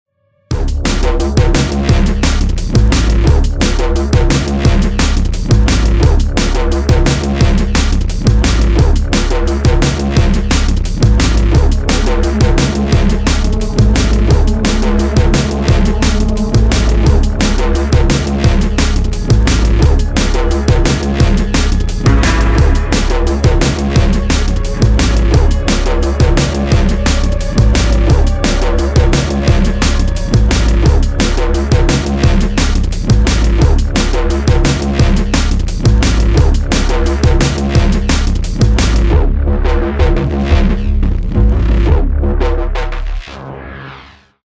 drum&bass
Drum & Bass